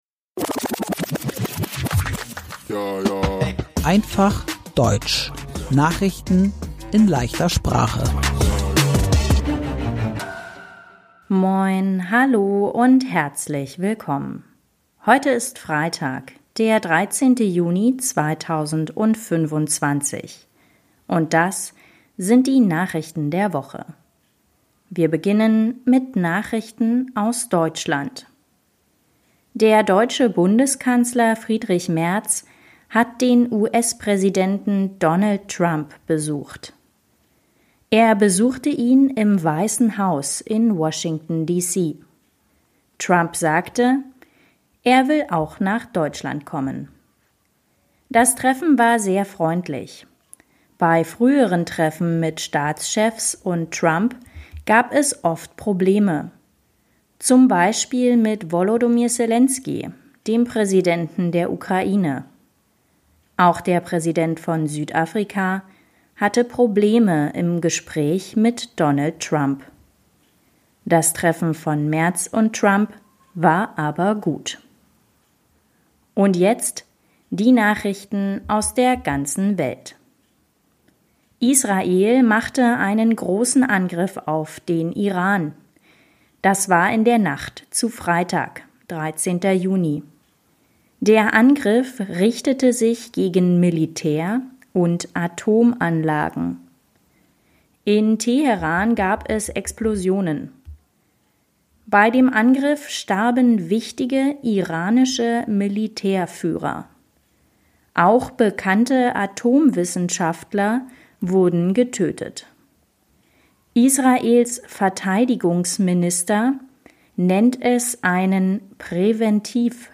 Einfach Deutsch: Nachrichten in leichter Sprache